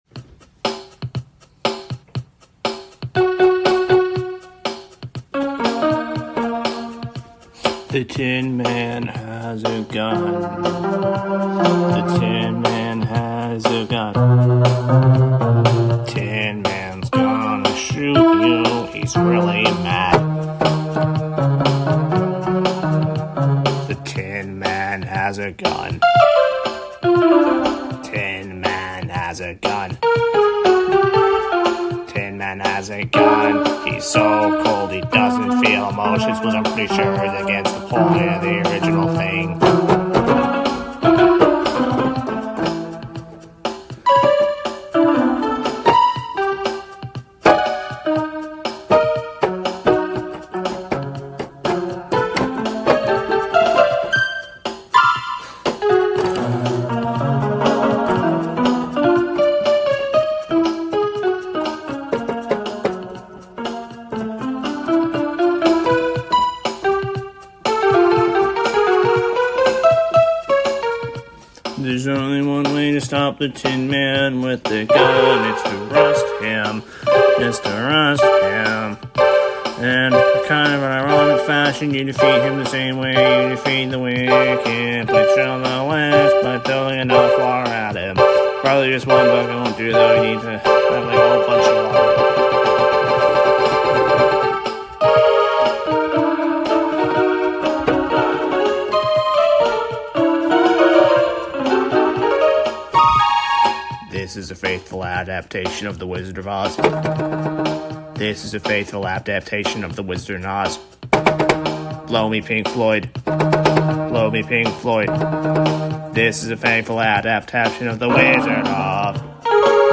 DIY bedroom pop classic